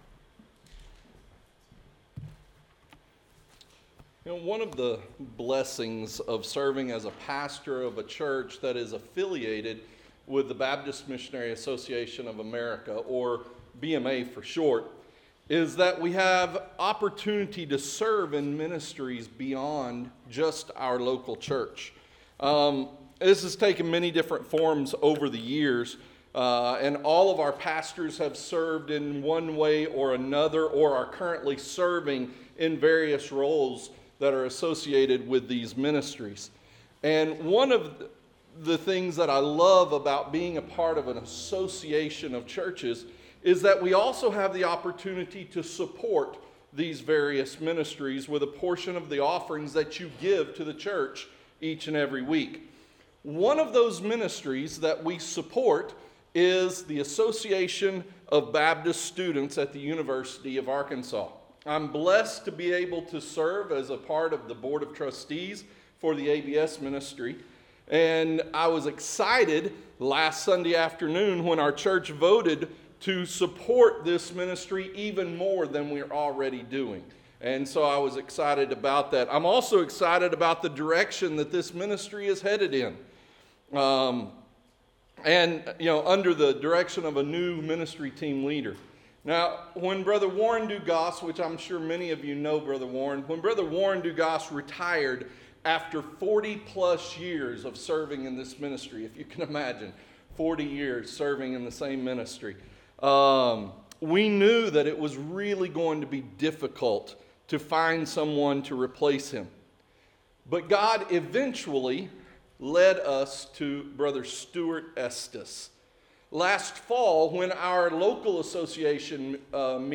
Interactive Sermon Notes Recharge – Full PDF Download Recharge – Week 6 Download Series: LUKE - The Starting Point of Christ's Ministry , LUKE-In the Steps of the Savior